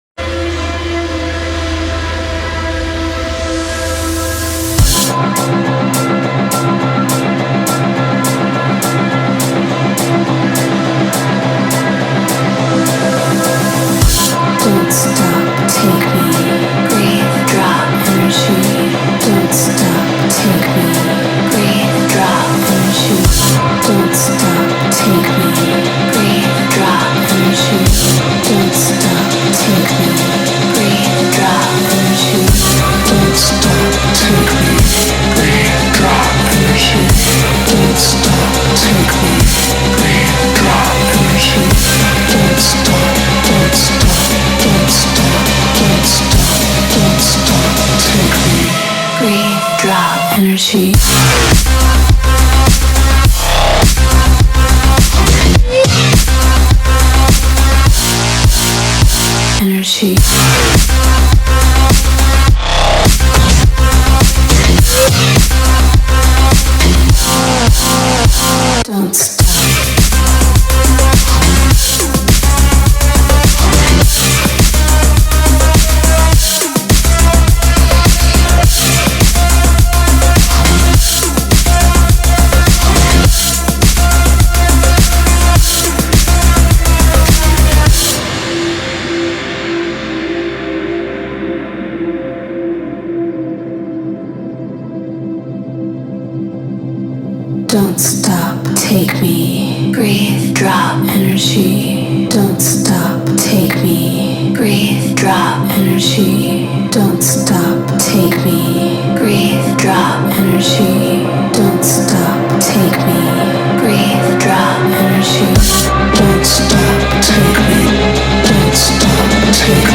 • Жанр: Dubstep